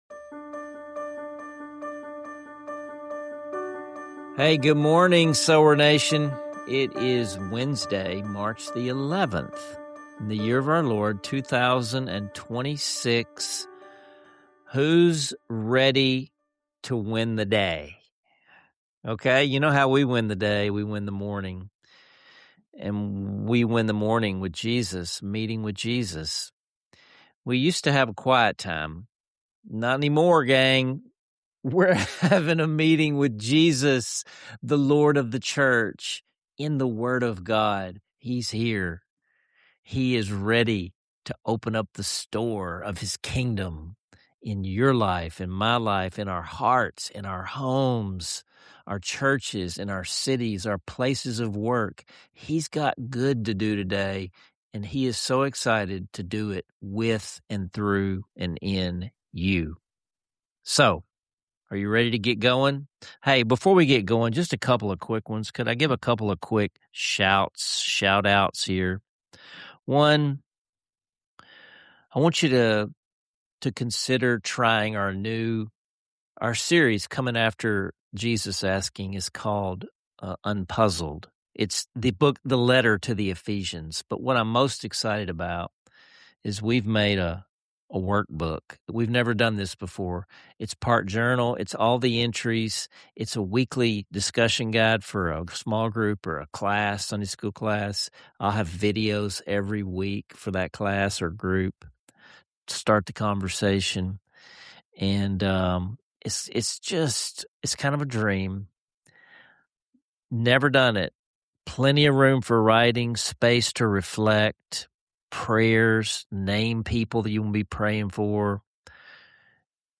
Join us for honest reflections, joyful singing, and actionable journal prompts to receive Jesus’ baptismal blessing into your own life.